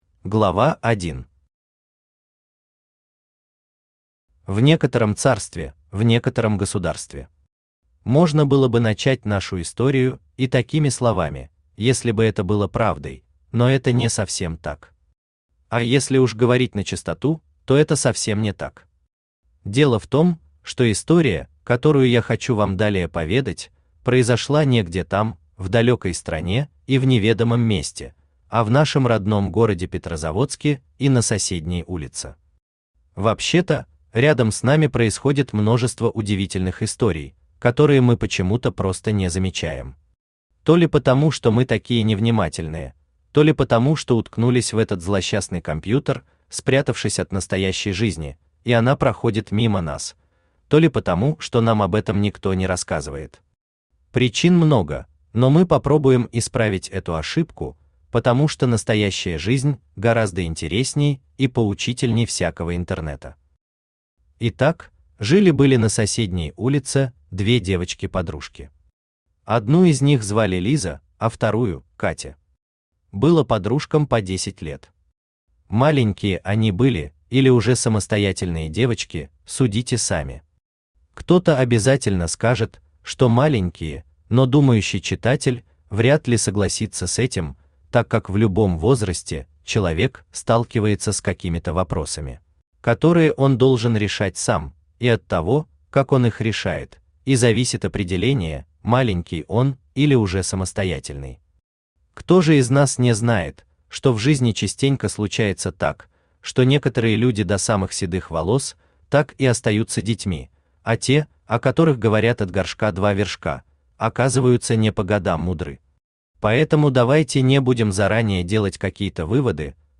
Аудиокнига Лесная сказка для детей и взрослых | Библиотека аудиокниг
Aудиокнига Лесная сказка для детей и взрослых Автор Сергей Марксович Бичуцкий Читает аудиокнигу Авточтец ЛитРес.